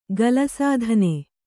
♪ gala sādhane